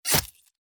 attack_hit_2.mp3